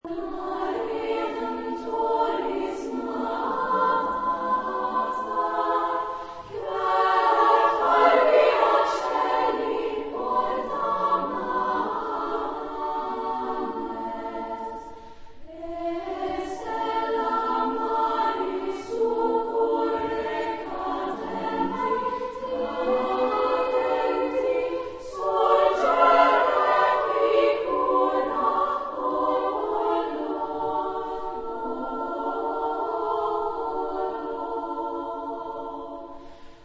Genre-Style-Forme : Pièce chorale ; Sacré
Type de choeur : SSSAAA  (6 voix égales de femmes )
Tonalité : la majeur ; si majeur
Consultable sous : 20ème Sacré Acappella